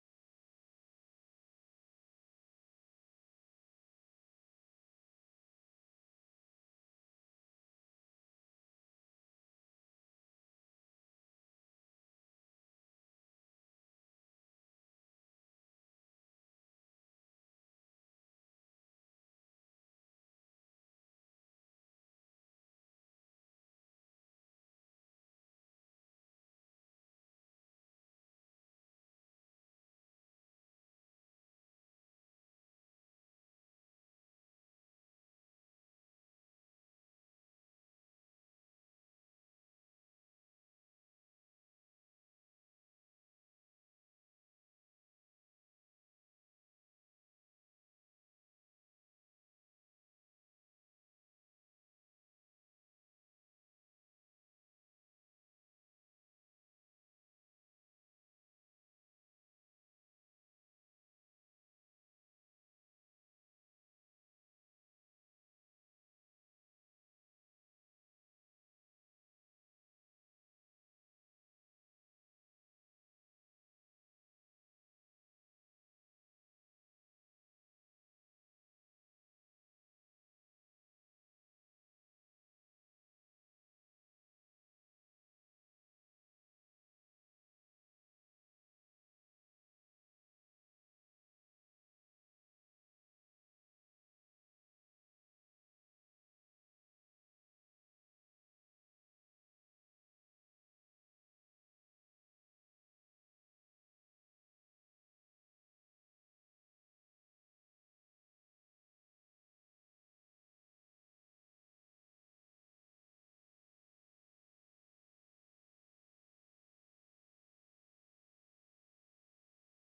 A 39 años de la gesta histórica, se realizaron dos actos para conmemorar el Día del Veterano y de los Caídos en la Guerra de Malvinas. El primero tuvo lugar en el monolito ubicado en la Plaza Dardo Rocha de Necochea, mientras que el cierre del homenaje se llevó a cabo en el Monumento de Quequén.